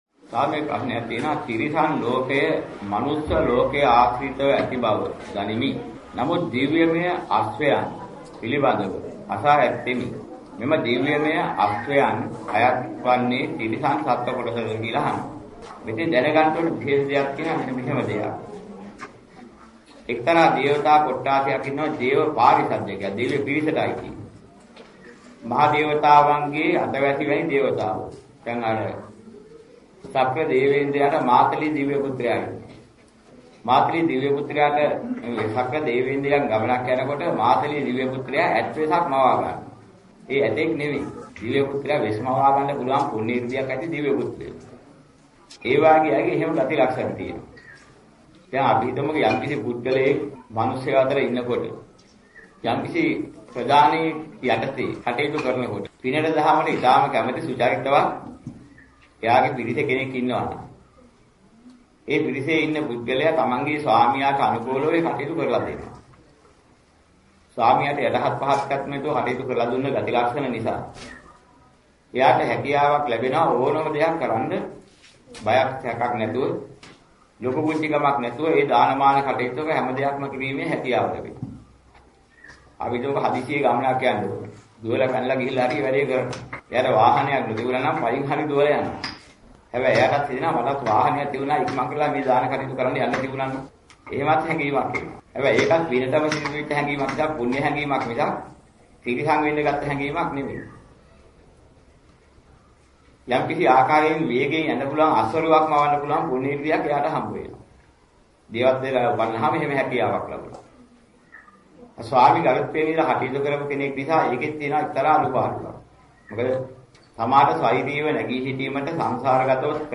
මෙම දේශනාවේ සඳහන් වන ධර්ම කරුණු: